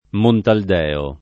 Montaldeo [ montald $ o ]